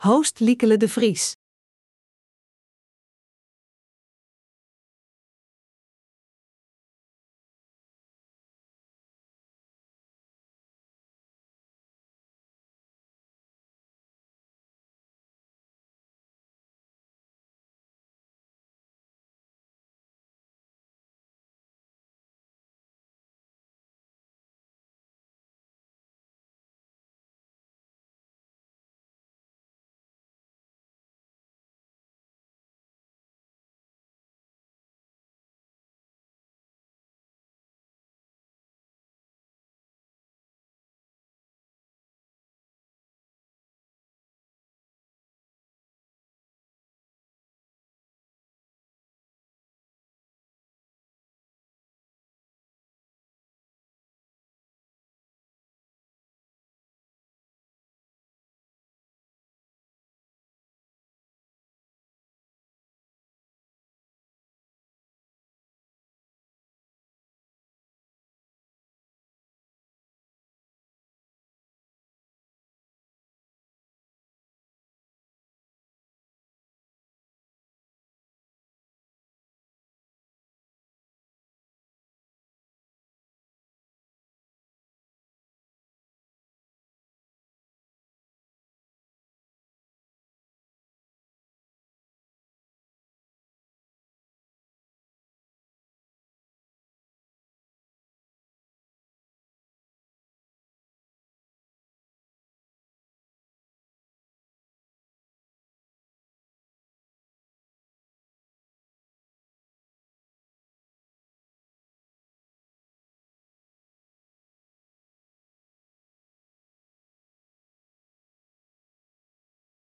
In dit webinar wordt toegelicht wat Neurotechnologie is.